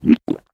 drink_honey1.ogg